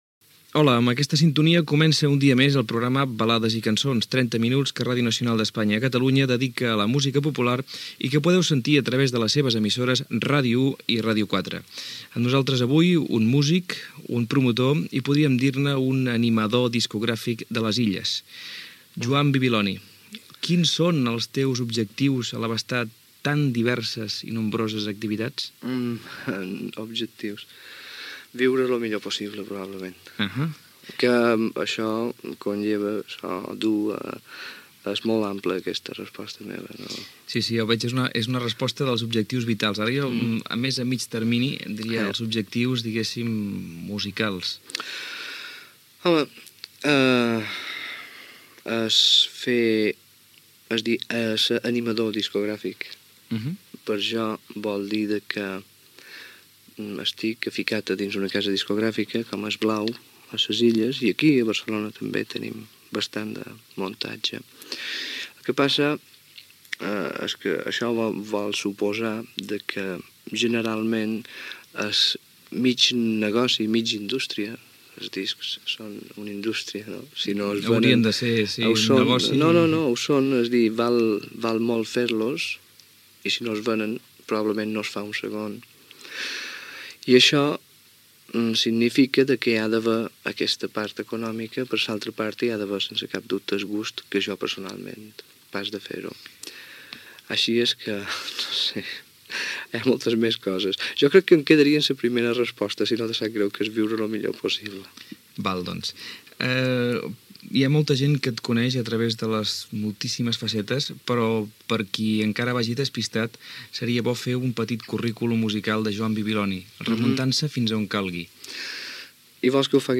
Identificació del programa i entrevista al músic
Gènere radiofònic Musical